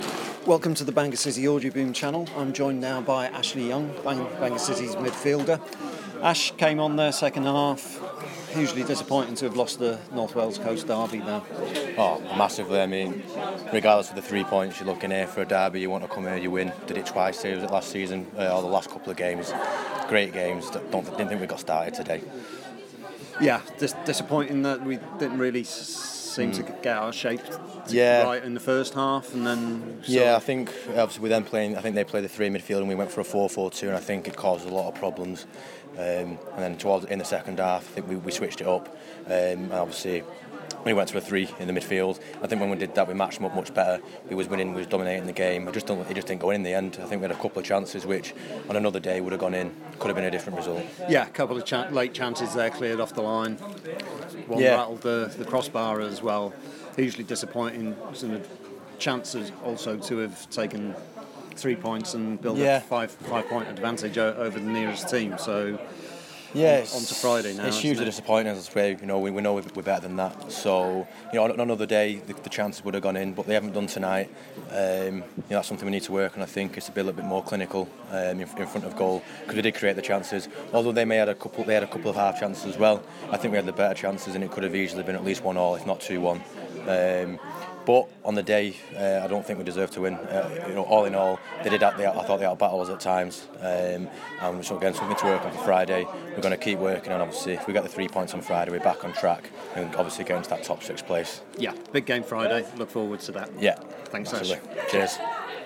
Citizens Interview